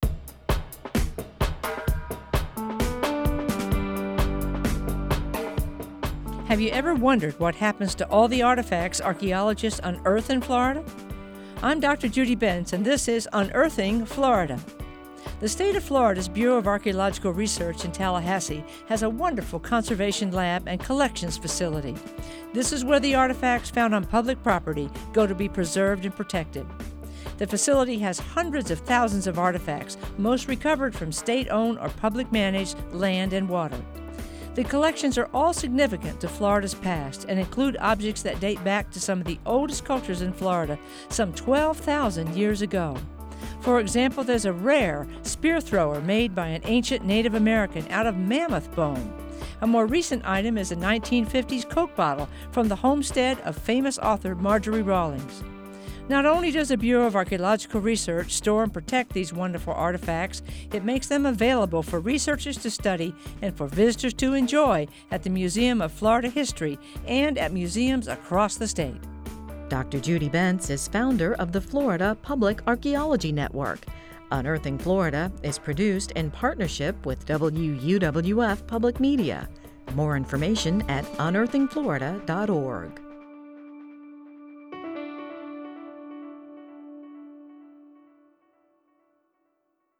Written, narrated, and produced by the University of West Florida, the Florida Public Archaeology Network and WUWF Public Media.